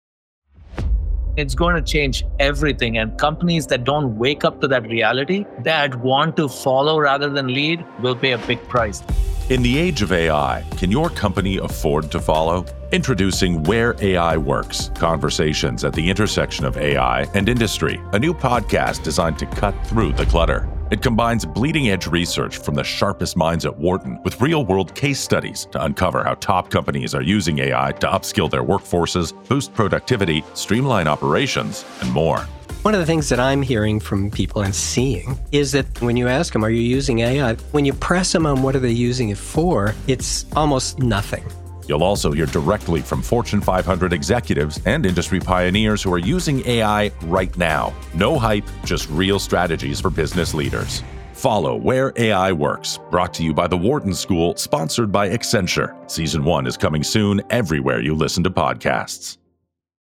Conversations at the Intersection of AI and Industry